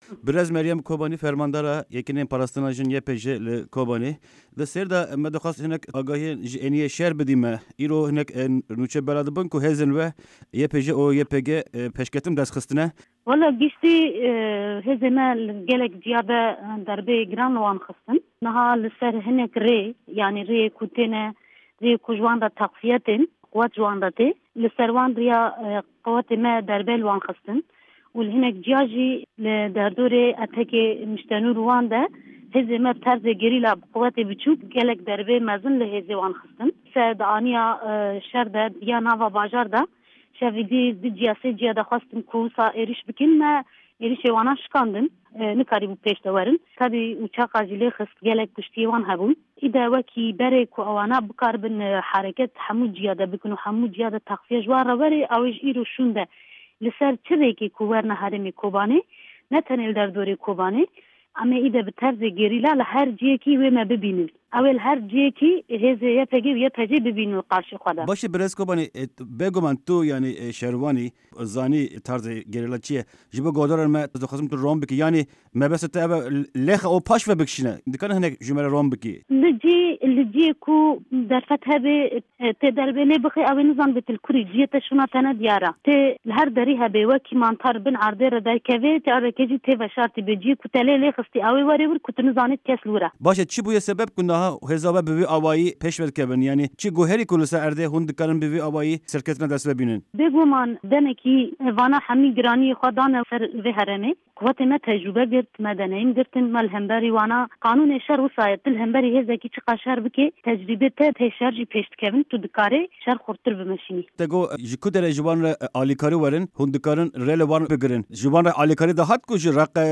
hevpeyvîna taybet